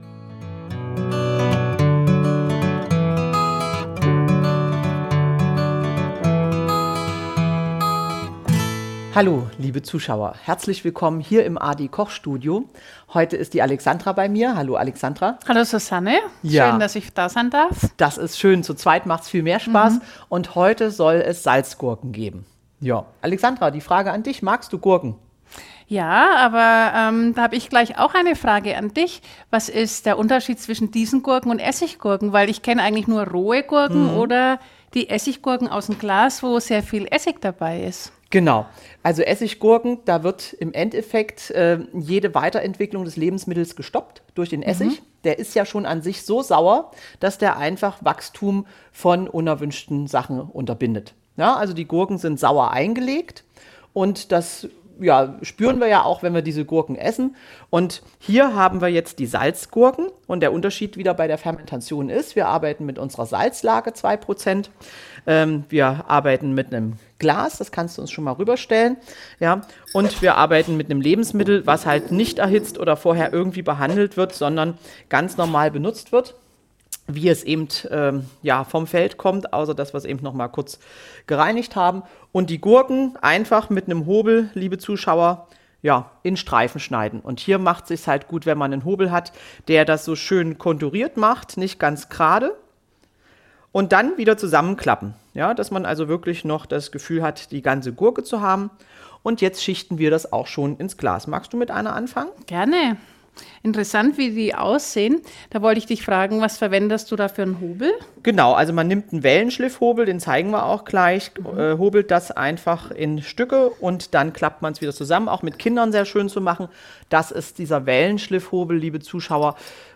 Im AD-Koch-Studio dreht sich heute alles um das köstliche Thema Salzgurken.